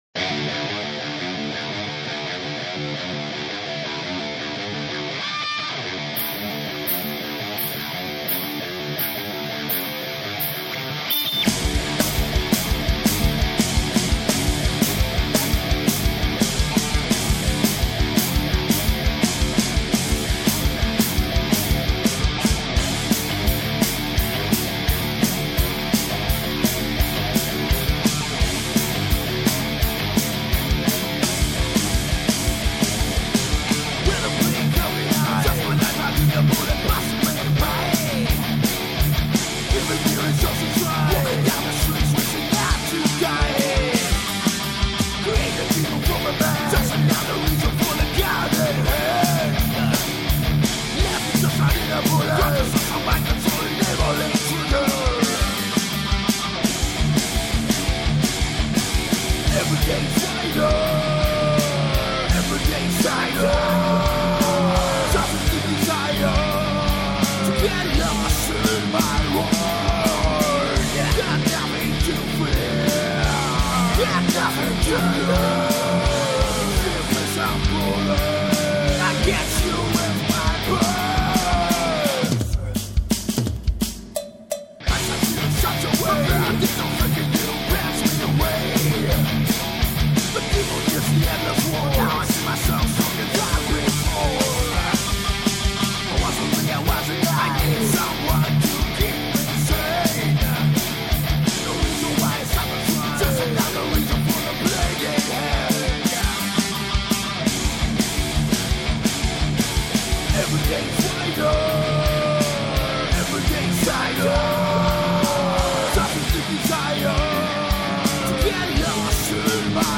ΔΕΥΤΕΡΟ ΠΡΟΓΡΑΜΜΑ Ροκ Συναναστροφες Ροκ Συναναστροφες Αφιερώματα Επετειακά Μουσική Συνεντεύξεις